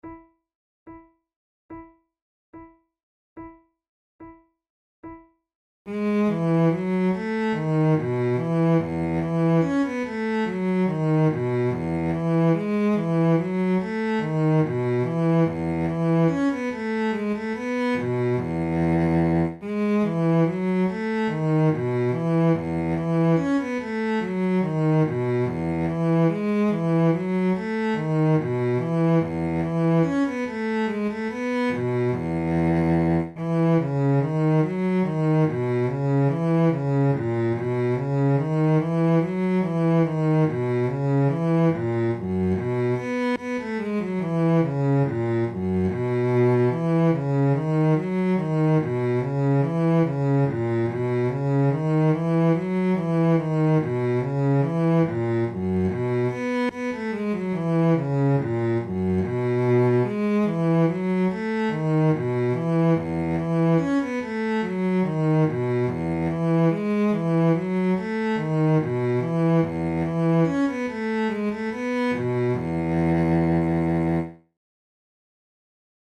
Sheet Music MIDI MP3 Accompaniment: MIDI